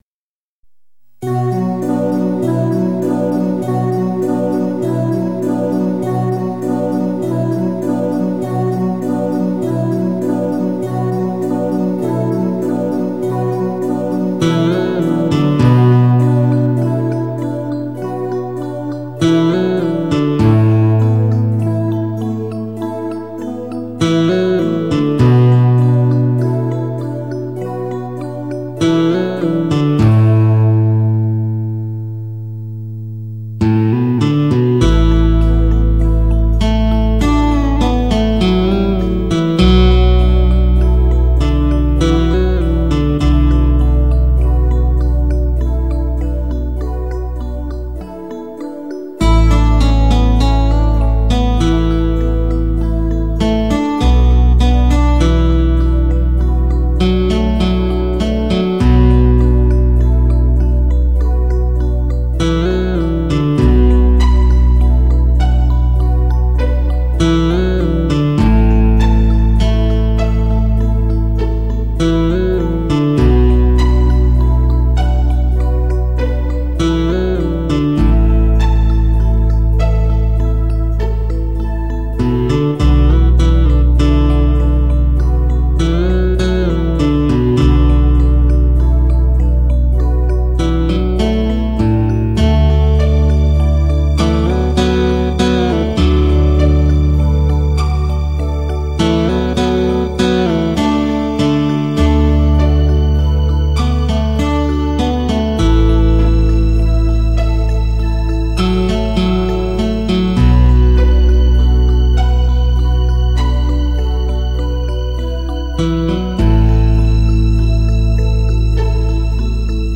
音质极度传真 音响测试器材之首选天碟
音色精细无遗 极度传真